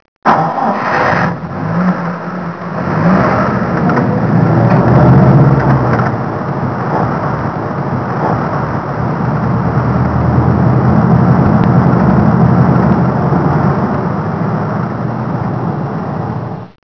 jeep_start.wav